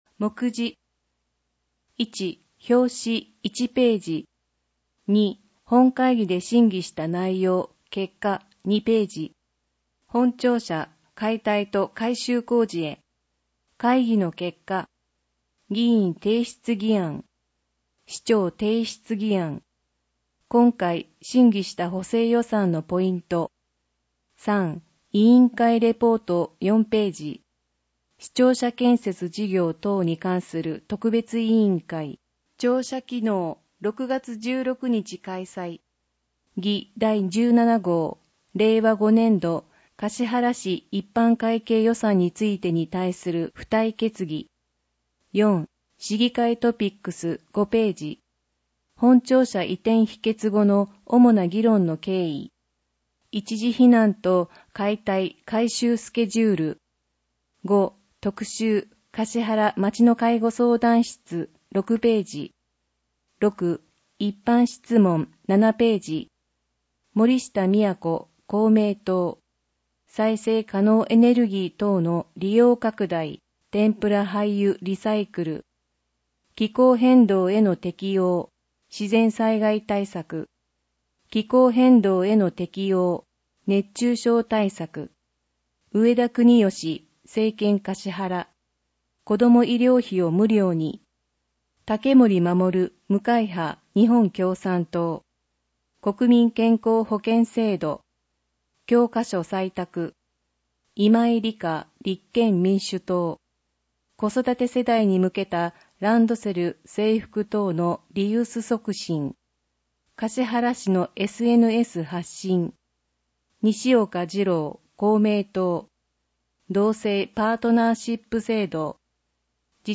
音訳データ
音訳データは、音訳グループ「声のしおり」の皆さんが音訳されたものを使用しています。